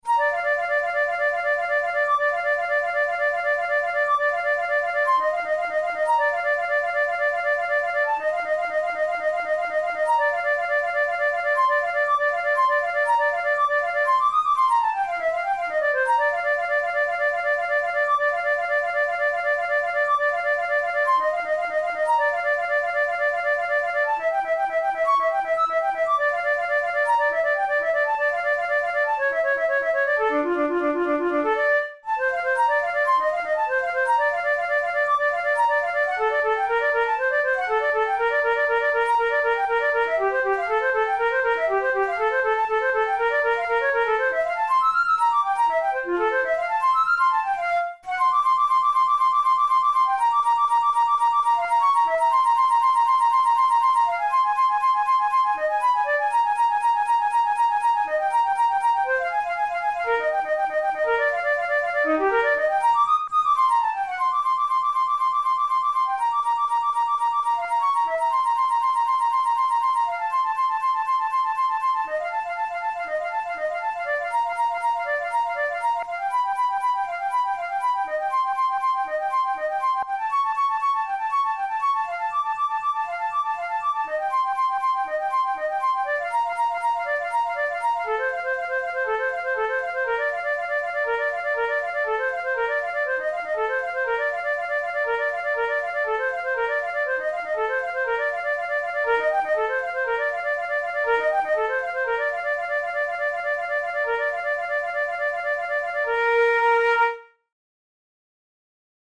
Categories: Etudes Romantic Written for Flute Difficulty: intermediate